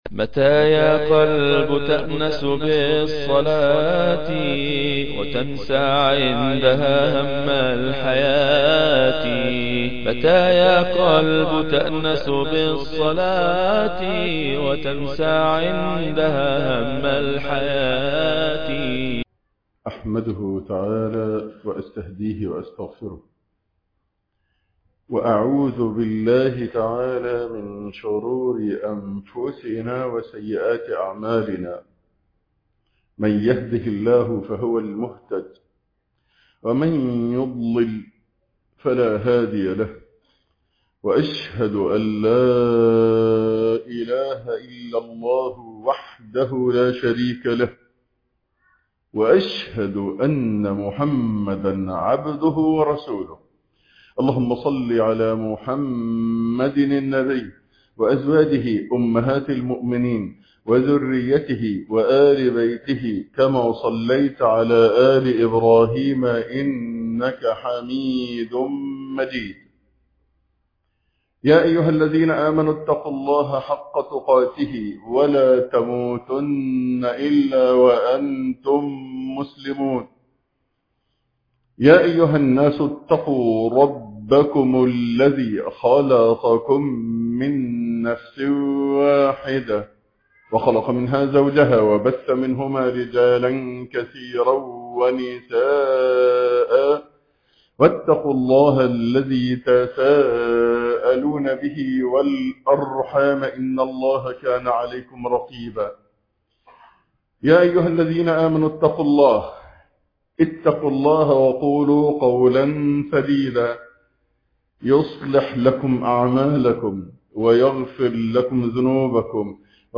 سفيان واللص - الدعوة إلى الله -خطبة الجمعة